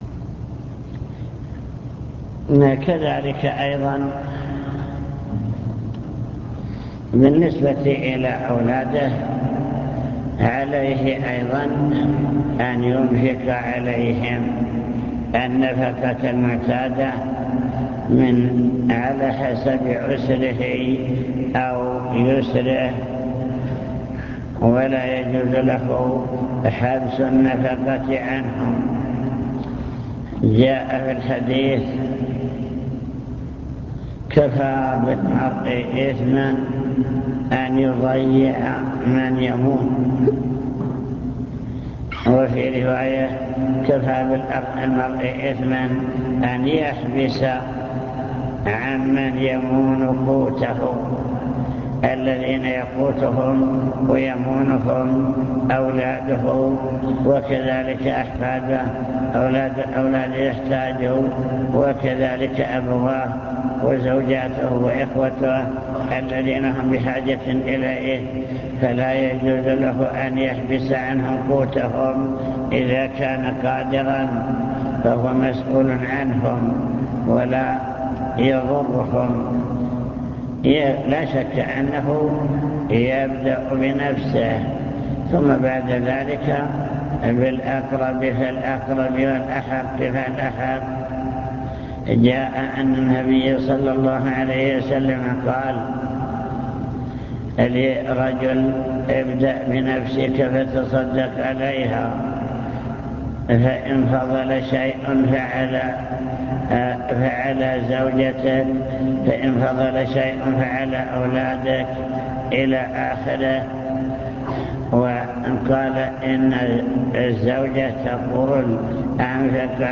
المكتبة الصوتية  تسجيلات - محاضرات ودروس  محاضرة واجب المسلم نحو أسرته واجب الرجل نحو أسرته في الأمور الدنيوية